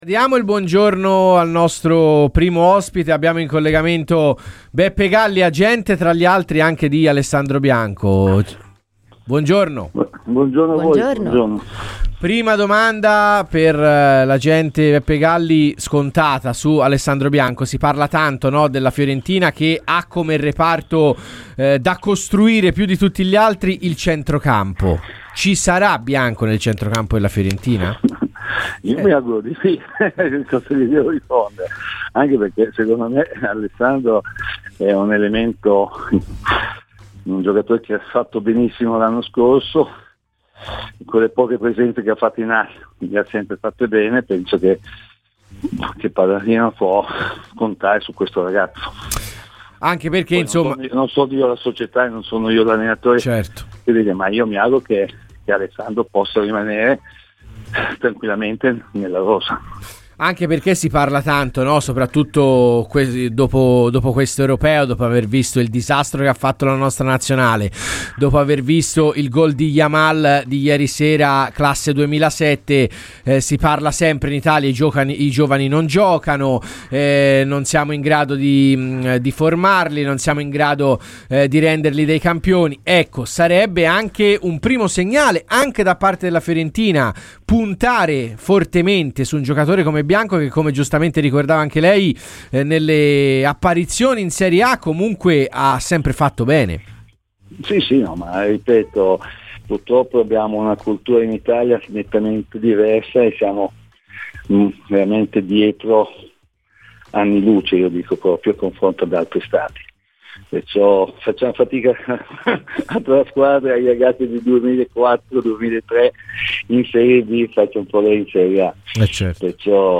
agente di mercato intervenuto a Radio FirenzeViola